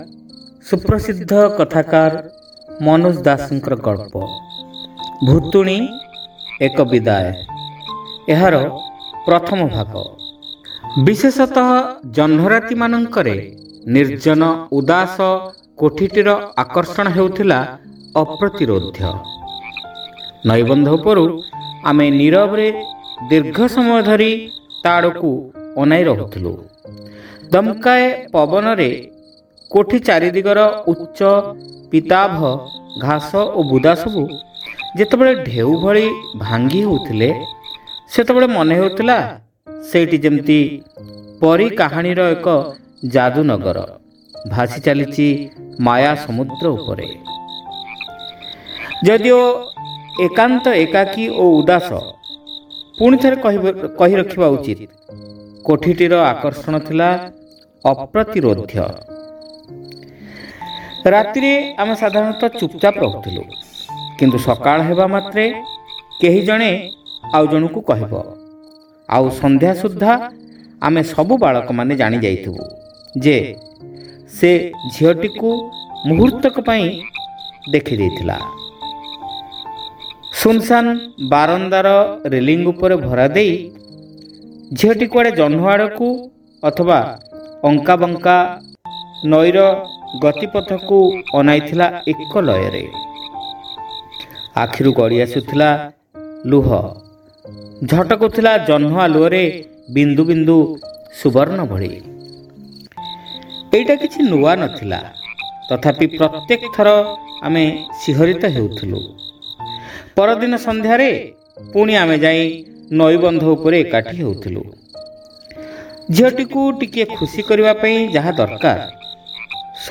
ଶ୍ରାବ୍ୟ ଗଳ୍ପ : ଭୁତୁଣୀ ଏକ ବିଦାୟ (ପ୍ରଥମ ଭାଗ)